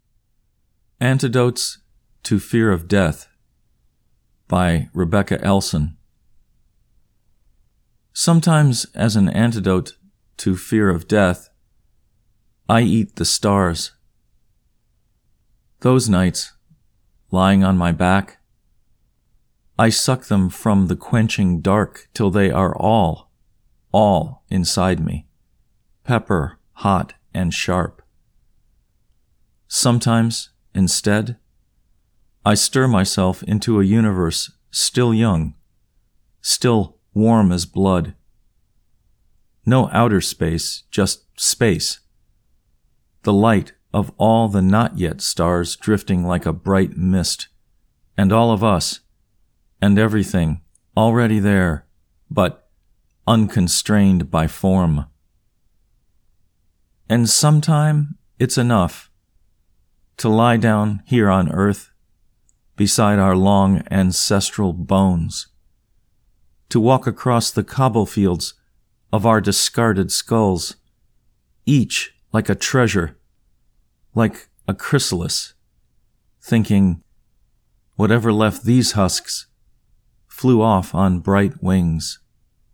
Antidotes to Fear of Death © by Rebecca Elson (Recitation)